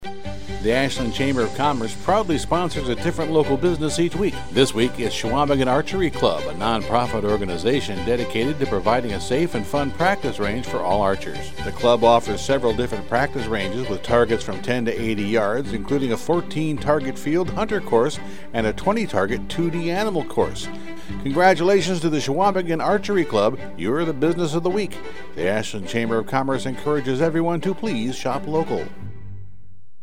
Each week the Ashland Area Chamber of Commerce highlights a business on Heartland Communications radio station WATW 1400AM and Bay Country 101.3FM. The Chamber draws a name at random from our membership and the radio station writes a 30-second ad exclusively for that business.